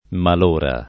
[ mal 1 ra ]